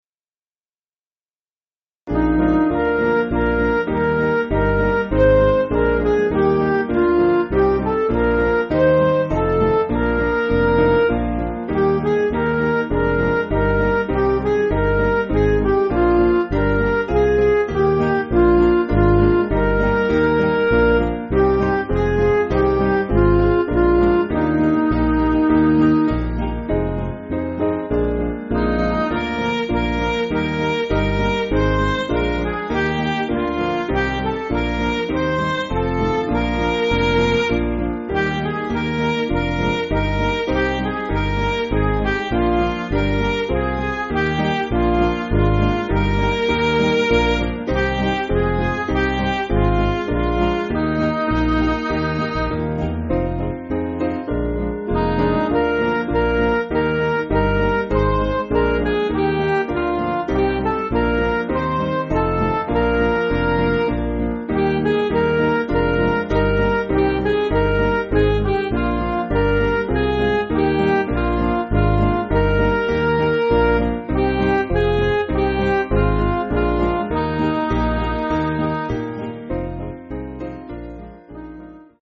Piano & Instrumental
(CM)   5/Eb